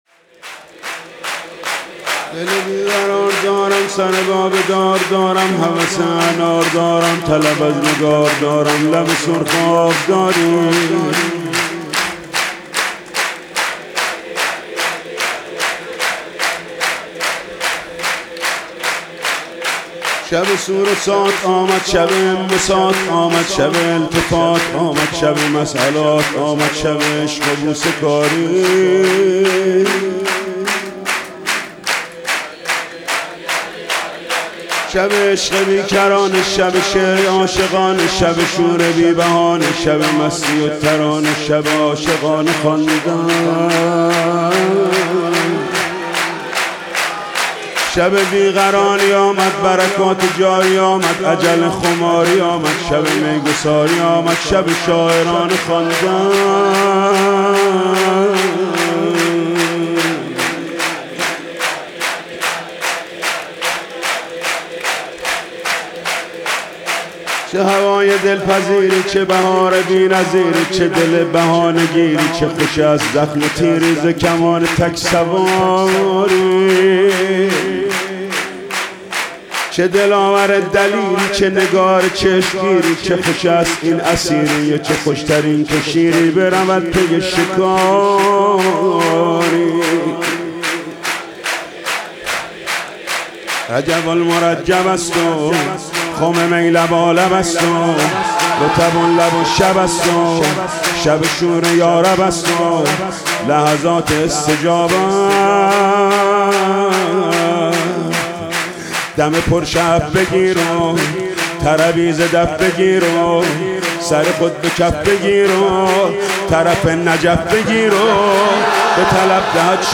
ولادت حضرت امیرالمومنین علی (ع) 1403